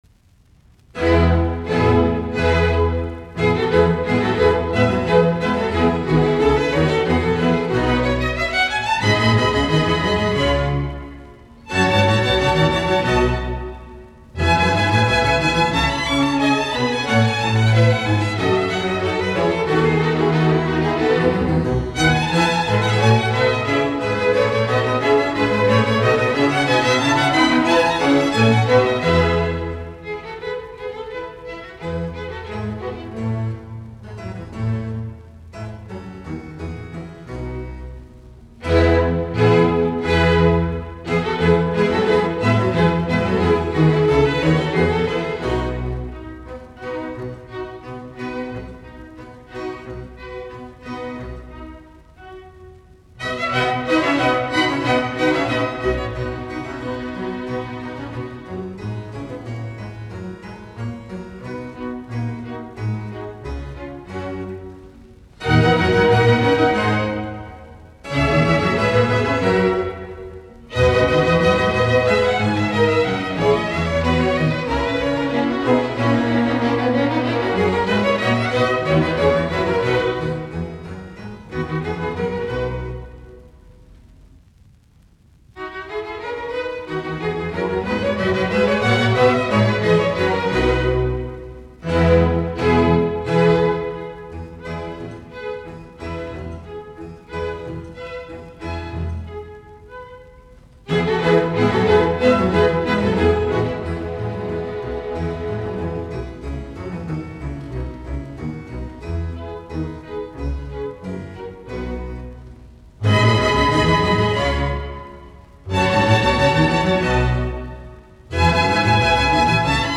Concerto for Violin and Orchestra No. 2 in E major : Add-a-soloist ; you play the solo part
Konsertot, viulu, jousiork., BWV1042, E-duuri; orkesteriosuus
musiikkiäänite